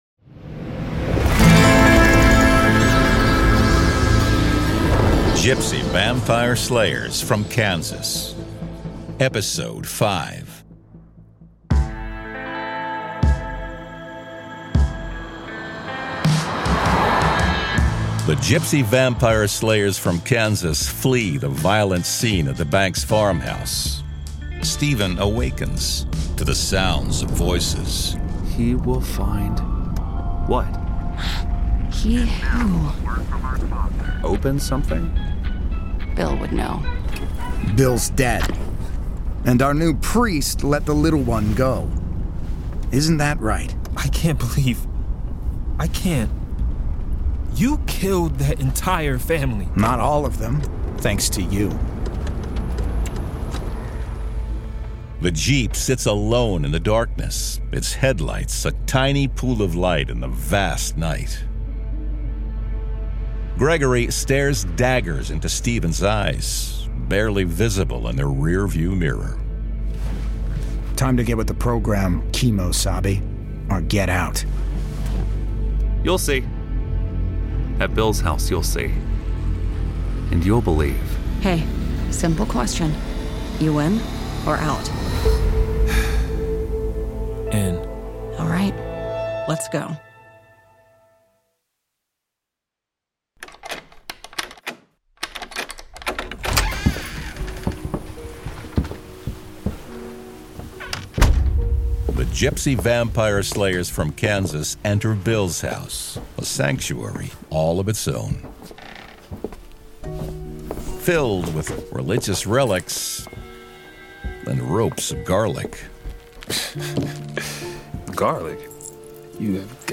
Format: Audio Drama
Voices: Full cast
Narrator: Third Person
Soundscape: Sound effects & music